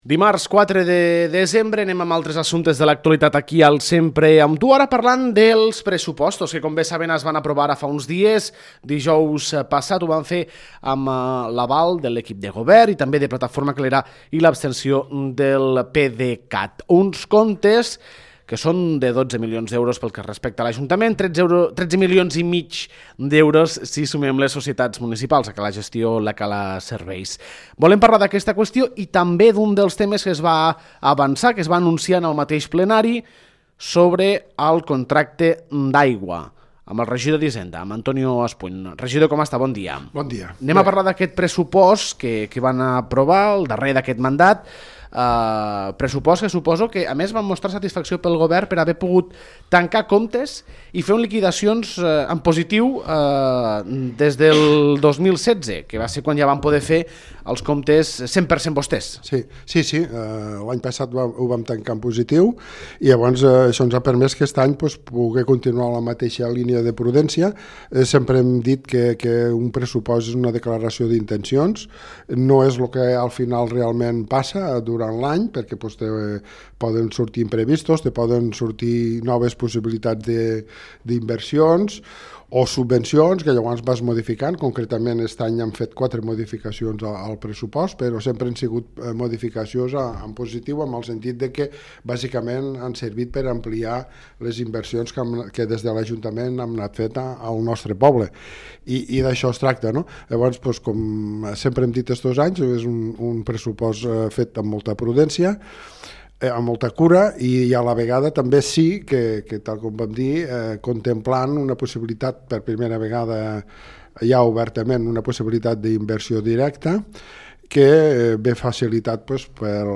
Dijous passat, el ple va aprovar els pressupostos del 2019 i també va rebutjar la petició d’incrementar el preu de l’aigua a Les Tres Cales, que havia sol·licitat la promotora de la urbanització. Parlem d’aquestes qüestions amb el regidor d’Hisenda, Antonio Espuny.